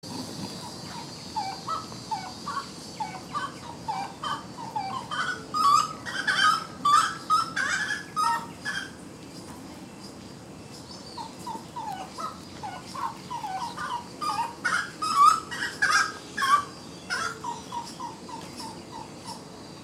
Saracuruçu (Aramides ypecaha)
Nome em Inglês: Giant Wood Rail
Fase da vida: Adulto
Localidade ou área protegida: Reserva Ecológica Costanera Sur (RECS)
Condição: Selvagem
Certeza: Gravado Vocal